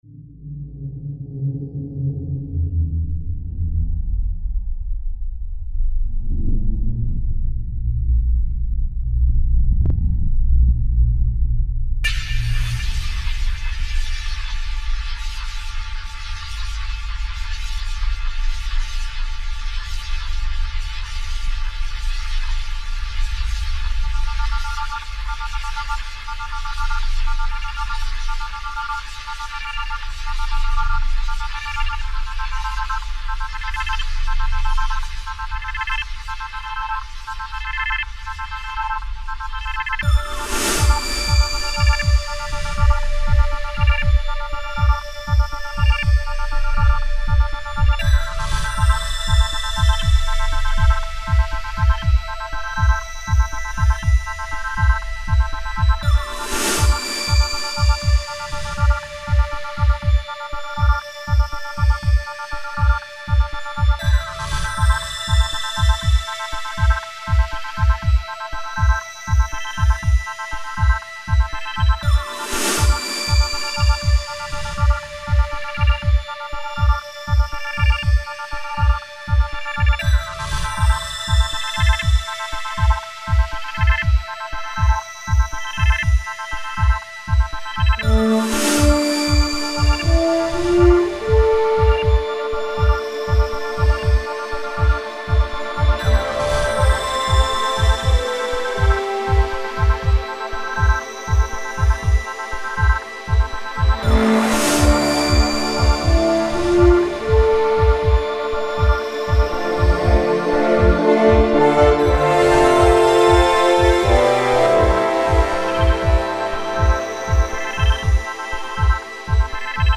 ambient classic blend